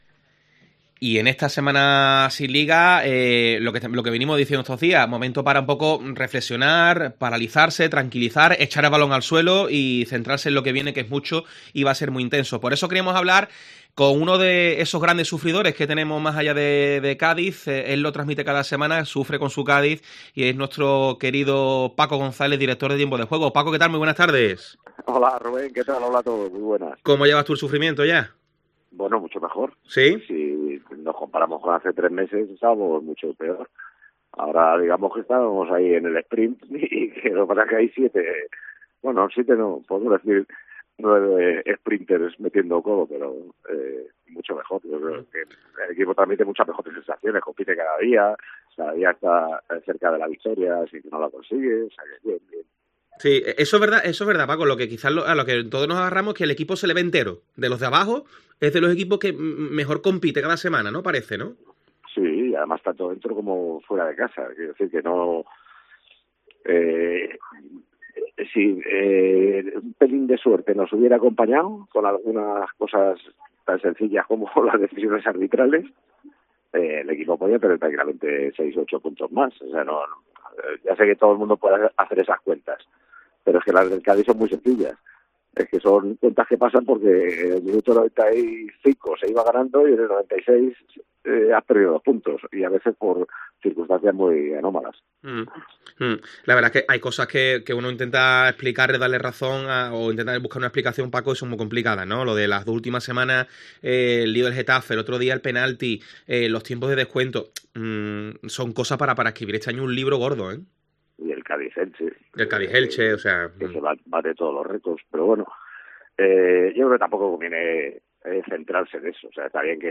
ESCUCHA LA ENTREVISTA A PACO GONZÁLEZ